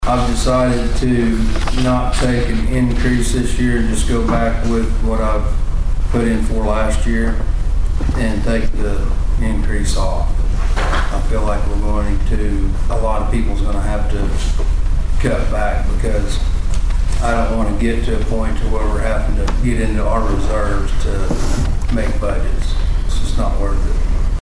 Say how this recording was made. At Monday's Board of Osage County Commissioners meeting, there was discussion regarding the 2025-2026 fiscal year budgets for the assessor's office, election board and planning and zoning department.